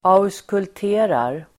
Ladda ner uttalet
Uttal: [a_oskult'e:rar]